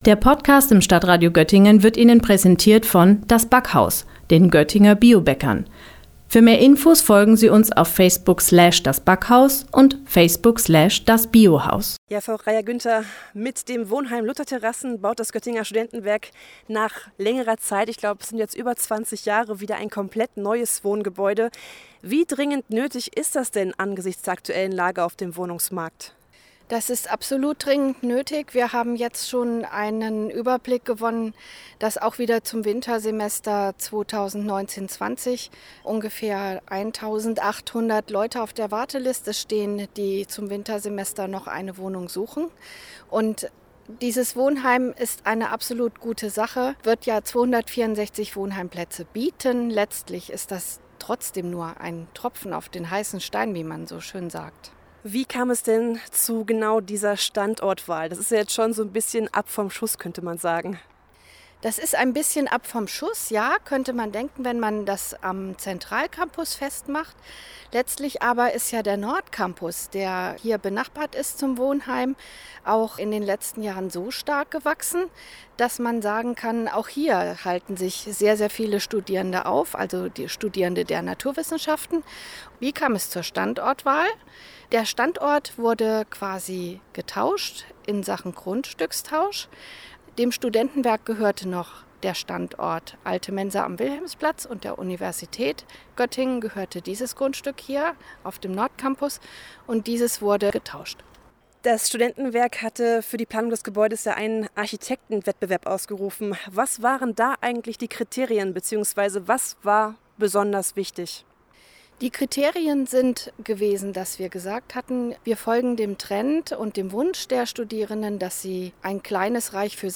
Beiträge > Richtfest für neues Wohnheim „Lutterterrasse“ des Göttinger Studentenwerks - StadtRadio Göttingen